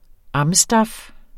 Udtale [ ˈɑmˌsdɑf ]